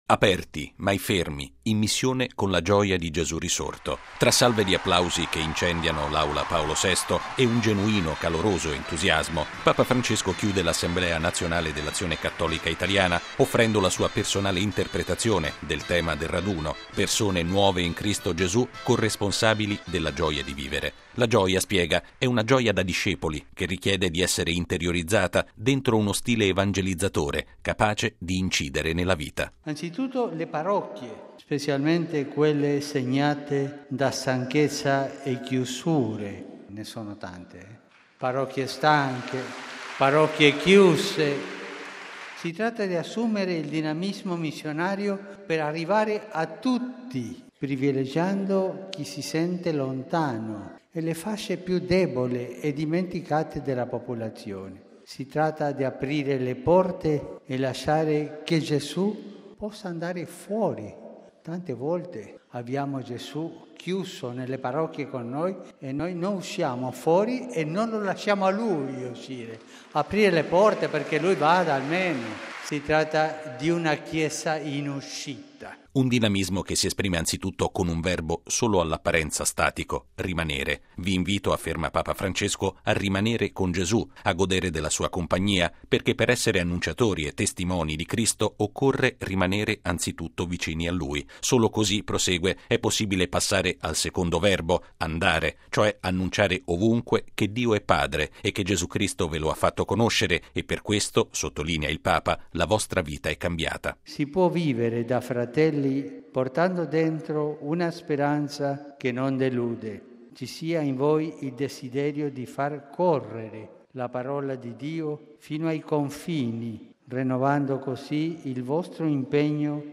Tra salve di applausi che incendiano l’Aula Paolo VI e un genuino entusiasmo, Papa Francesco chiude l’Assemblea nazionale dell’Azione Cattolica italiana offrendo la sua personale interpretazione del tema del raduno “Persone nuove in Cristo Gesù, corresponsabili della gioia di vivere”.